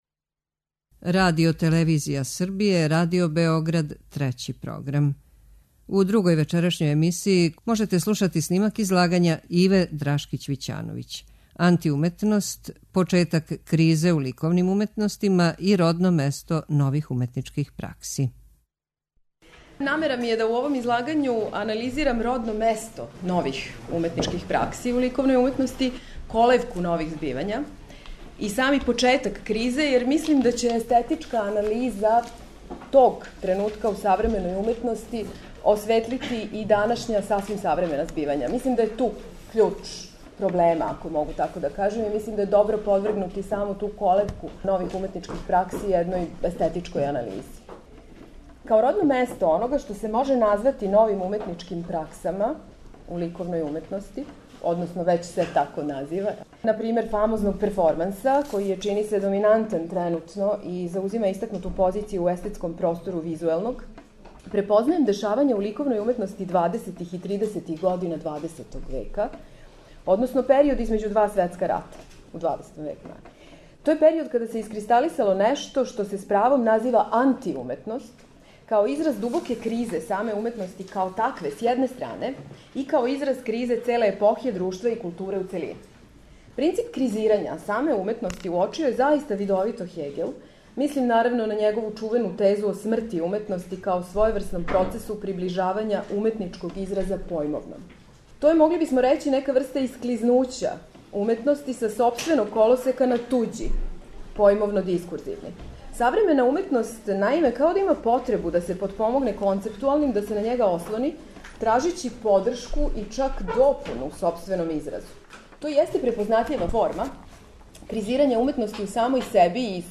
У две емисије, којима настављамо циклус КРИЗА УМЕТНОСТИ И НОВЕ УМЕТНИЧКЕ ПРАКСЕ, можете пратити снимке излагања са истоименог научног скупа одржаног средином децембра у организацији Естетичког друштва Србије.
Научни скупови